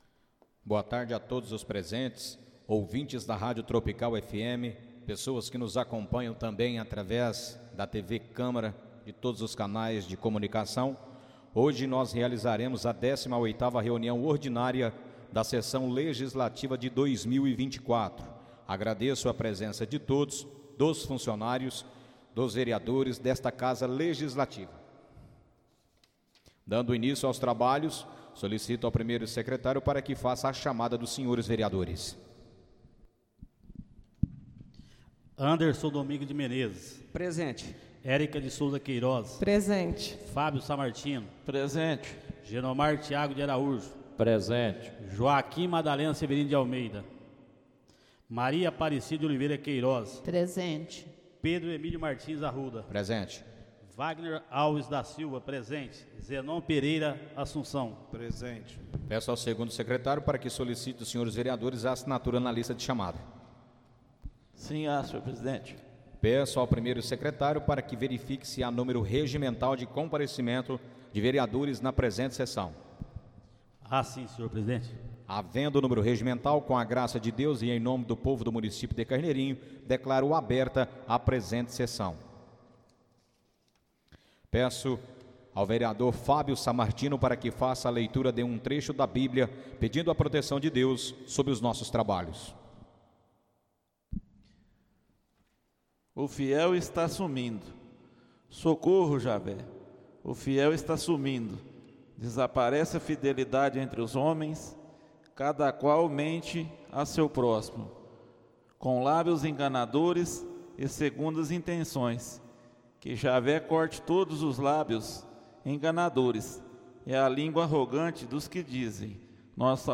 Áudio da 18.ª reunião ordinária de 2024, realizada no dia 04 de Novembro de 2024, na sala de sessões da Câmara Municipal de Carneirinho, Estado de Minas Gerais.